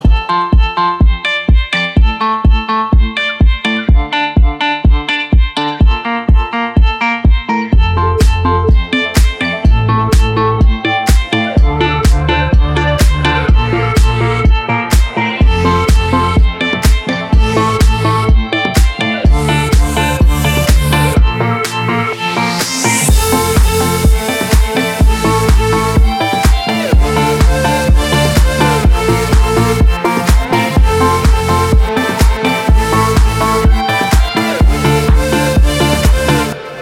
• Качество: 320, Stereo
гитара
громкие
спокойные
без слов
инструментальные
tropical house
house
Милая танцевальная музыка